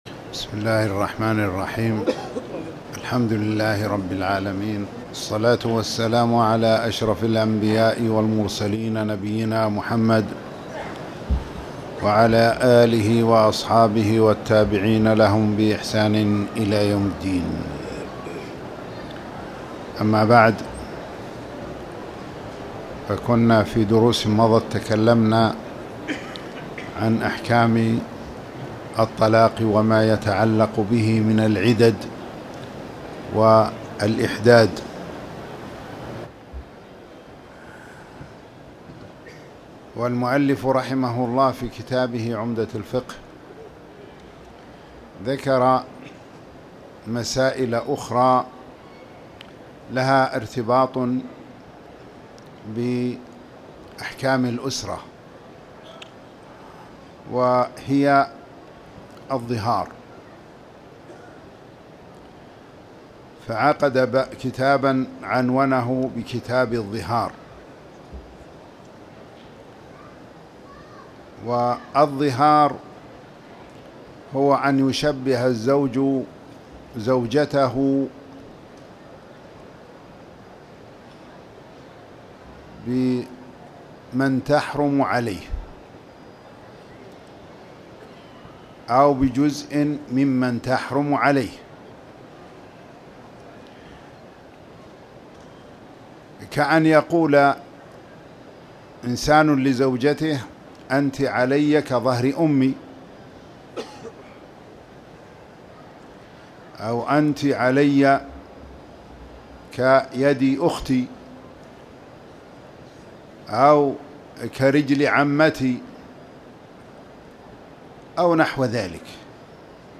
تاريخ النشر ١ رجب ١٤٣٨ هـ المكان: المسجد الحرام الشيخ